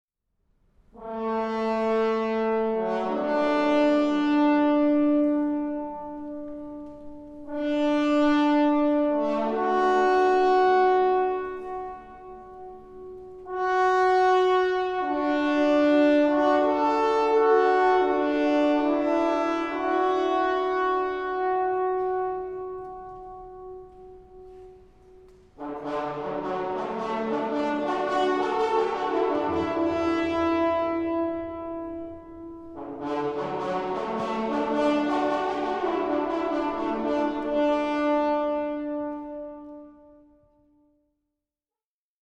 aus dem SWR-Funkturm auf der Hornisgrinde
Konzertmitschnitt vom 29. August 2009
Deckenhöhe "Konzertsaal": 150 m
play Naturhorn auf Plattform in 40 m Höhe
SWR-Funkturm-Hornisgrinde-Naturhorn.mp3